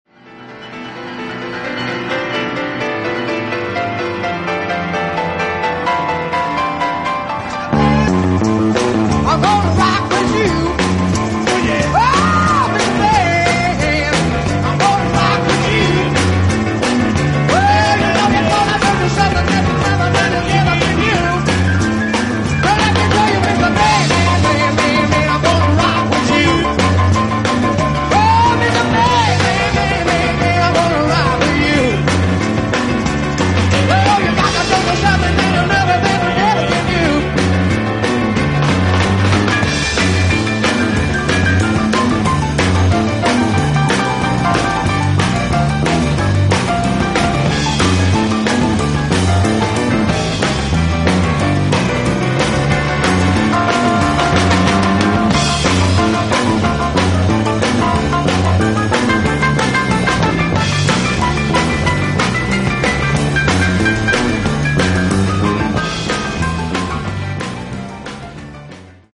piano player extraordinaire